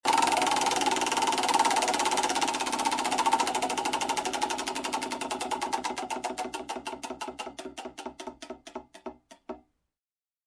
wheelspin.mp3